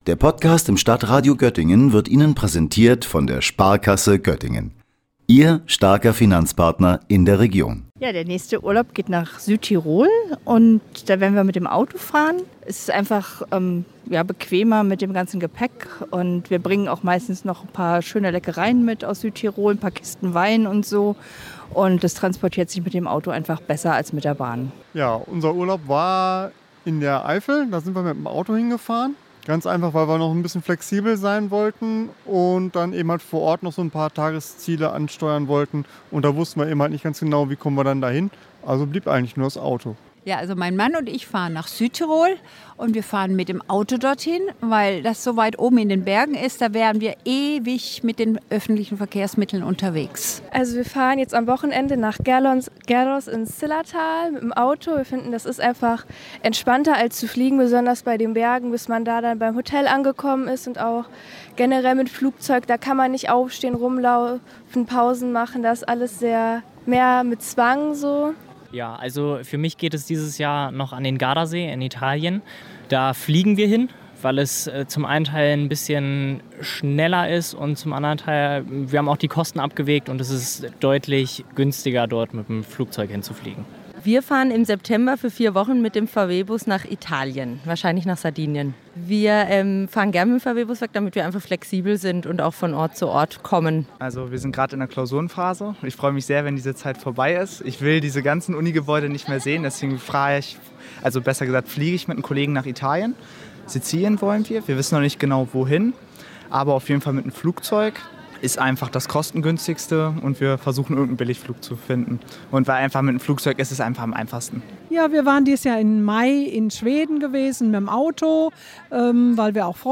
Wir haben uns in der Innenstadt umgehört und Passantinnen und Passanten gefragt, wie sie wohin verreisen werden oder bereits verreist sind.